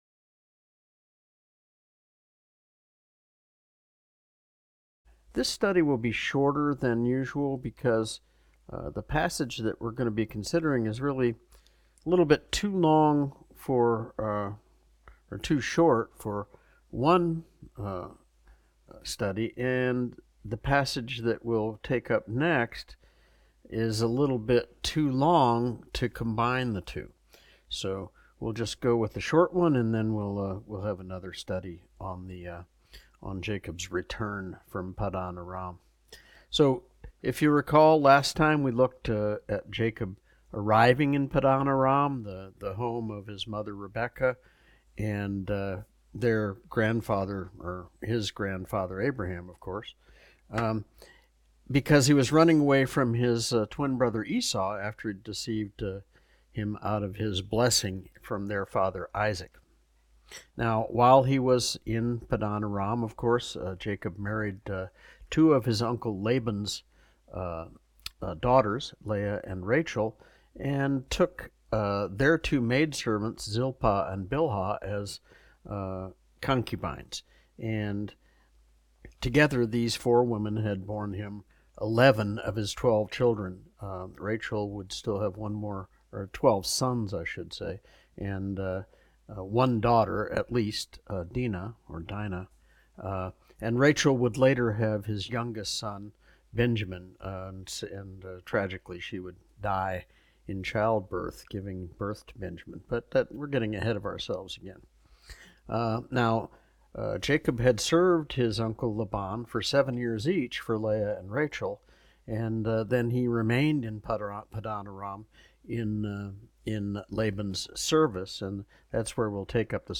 Jacob’s Prosperity in Paddan-aram Study Date - June 8, 2025 Study Type - Adult Lesson Series - Genesis 2024 Book(s) - Genesis divination , Jacob , Laban Genesis 30:25-43, Genesis 28:15 After serving his uncle Laban for fourteen years for his two daughters Leah and Rachel, Jacob continued in Laban’s service in exchange for a portion of livestock from Laban’s flocks and herds.